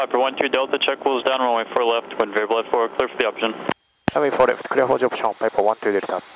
224　ATCの聞き取りお願いします NEW!
管制官の指示でコールサインのあと、????の部分、なんと言っているのでしょうか？
場所はカラエロア空港（PHJR）です。